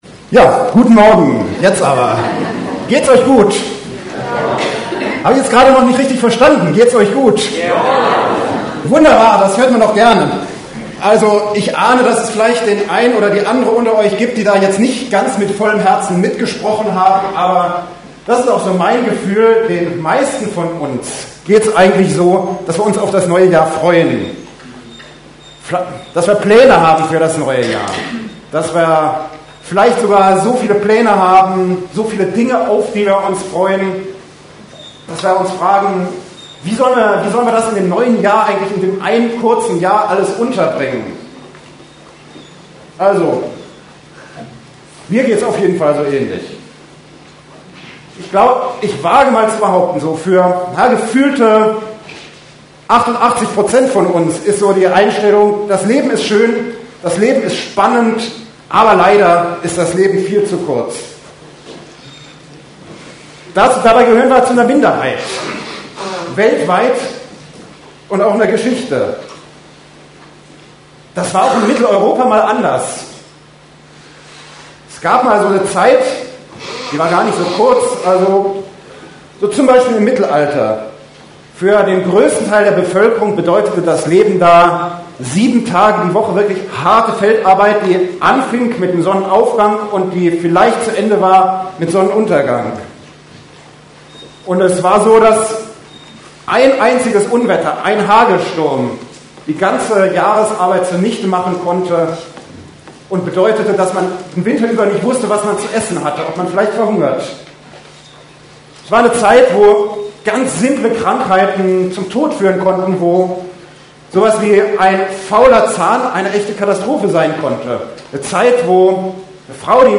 Einzelpredigten